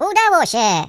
share/hedgewars/Data/Sounds/voices/Default_pl/Victory.ogg